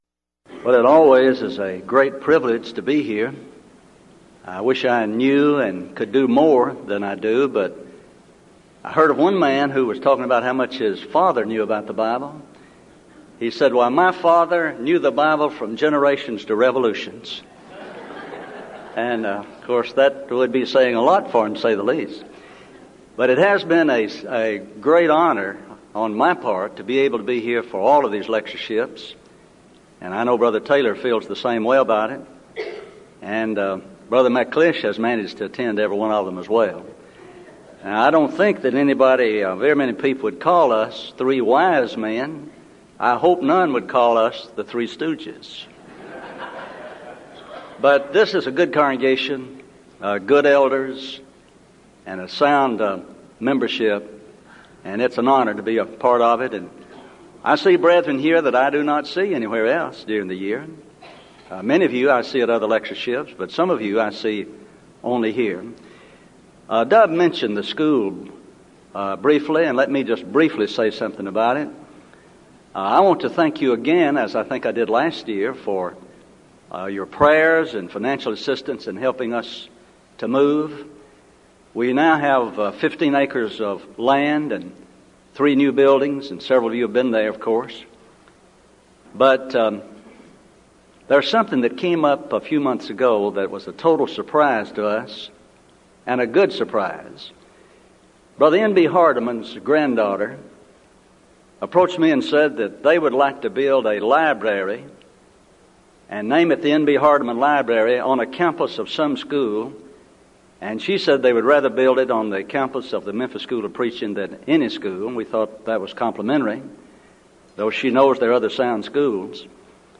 Event: 1998 Denton Lectures
lecture